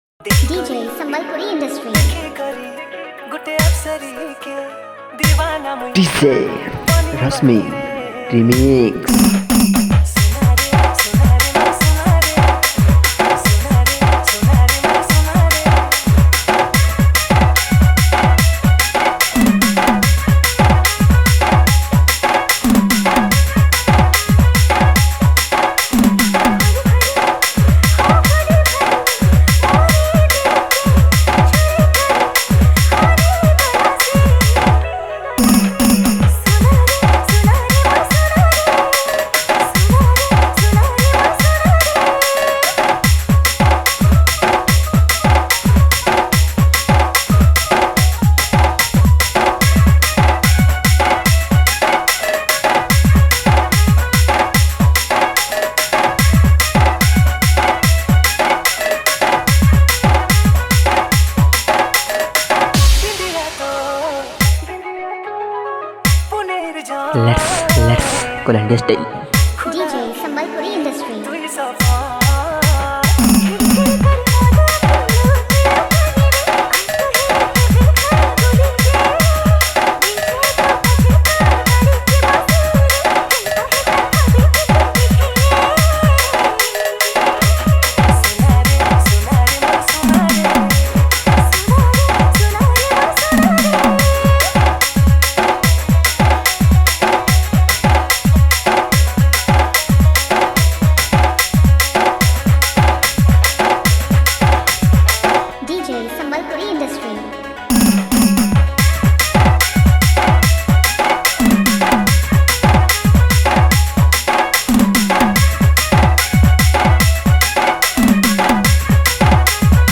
Sambalpuri Dj Song 2024
Category:  New Sambalpuri Dj Song 2023